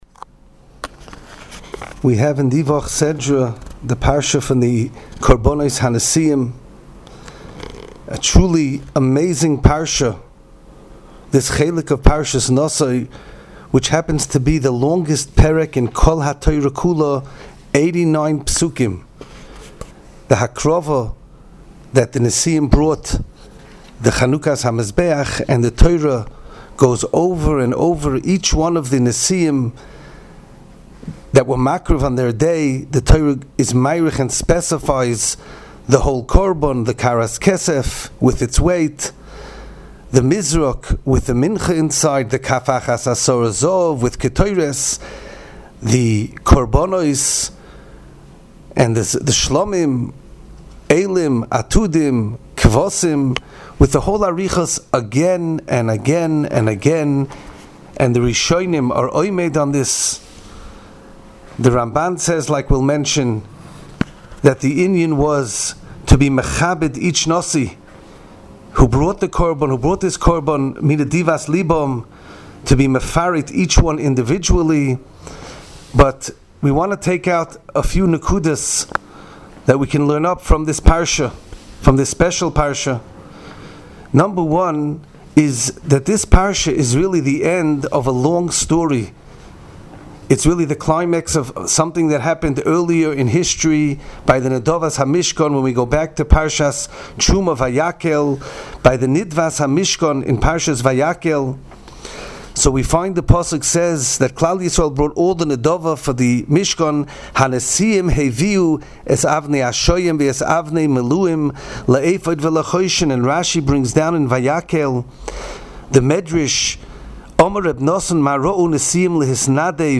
Divrei Torah, Shiurim and halacha on Parshas Naso from the past and present Rebbeim of Yeshivas Mir Yerushalayim.